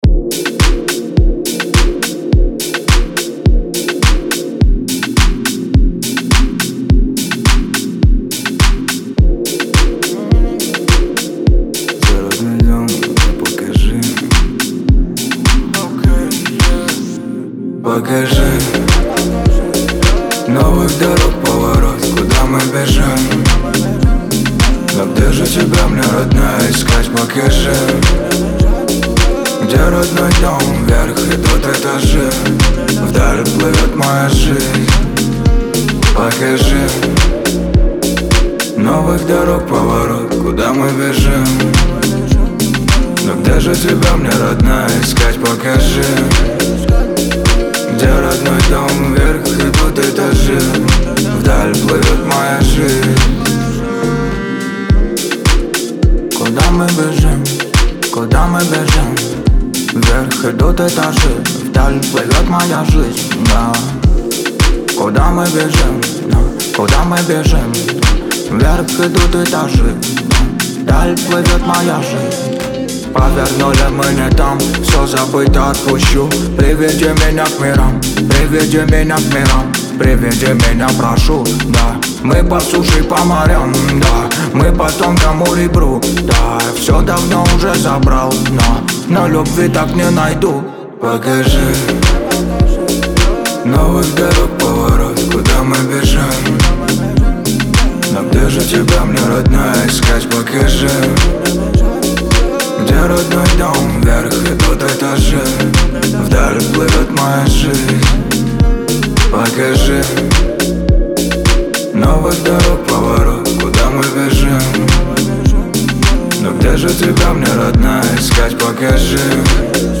это эмоциональный трек в жанре поп-рок